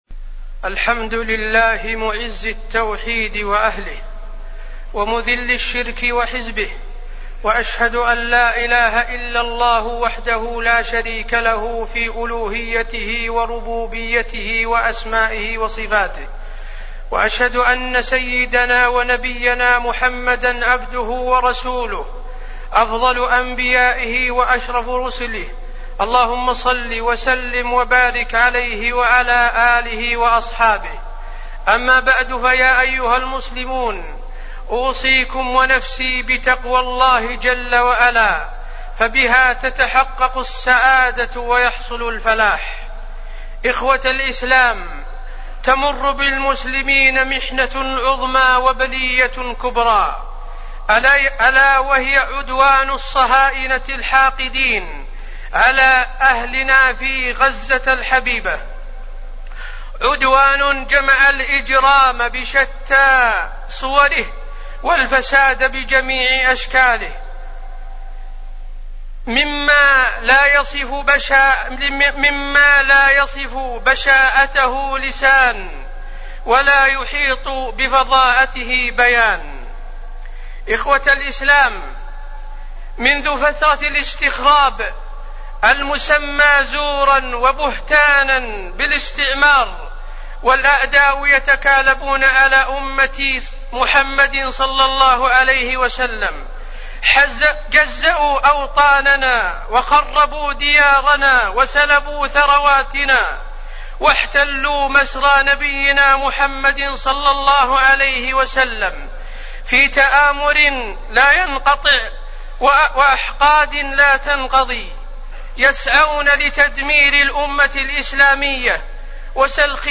تاريخ النشر ١٩ محرم ١٤٣٠ هـ المكان: المسجد النبوي الشيخ: فضيلة الشيخ د. حسين بن عبدالعزيز آل الشيخ فضيلة الشيخ د. حسين بن عبدالعزيز آل الشيخ أحداث غزة